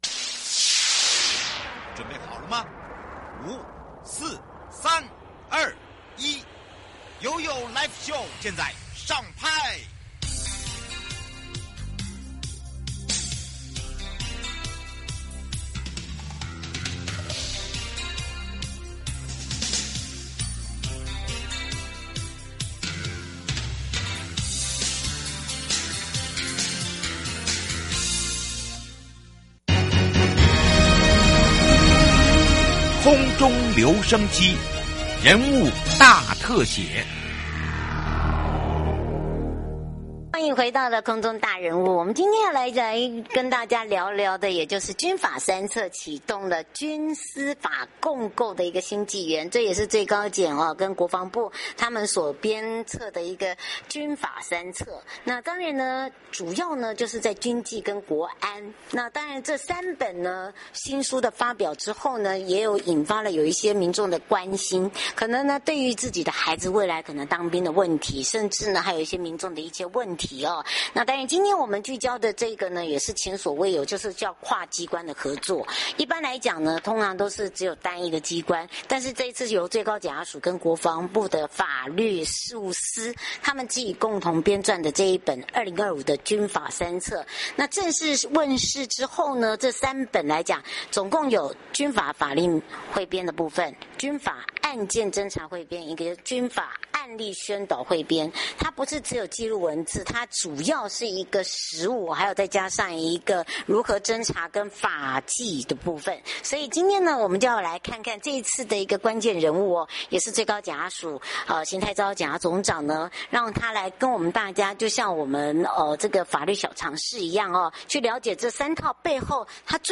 受訪者： 最高檢察署 邢泰釗 檢察總長 節目內容： 主題：營業秘密為何與國家安全有關？——從台積電案看科技機密